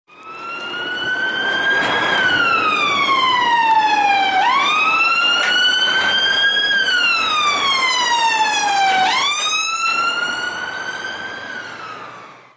3 police siren